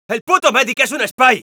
Category : Scout voice commands/es
Scout_cloakedspyidentify07_es.wav